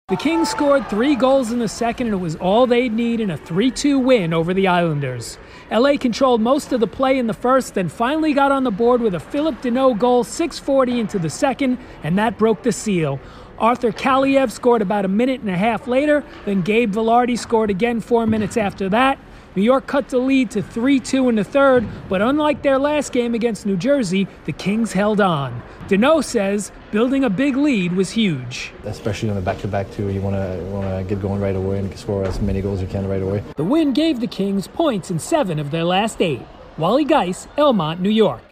The Kings hang on to beat the Islanders, 3-2. Correspondent